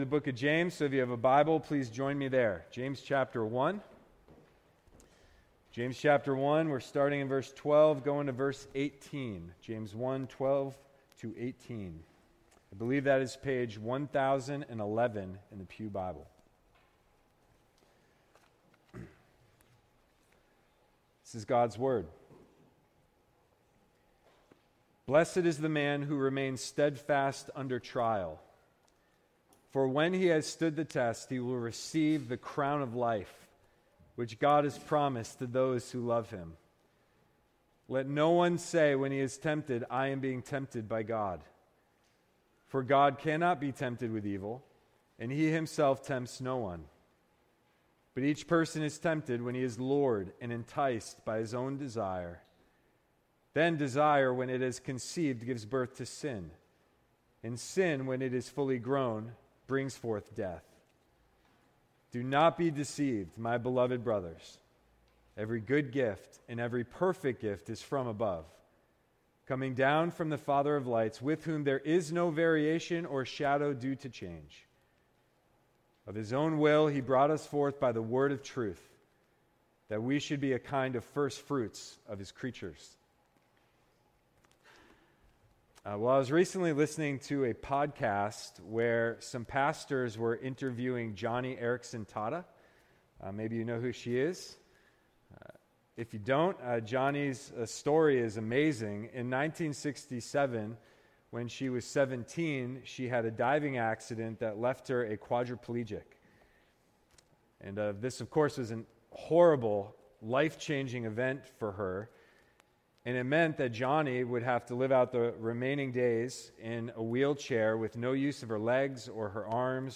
6-29-sermon.mp3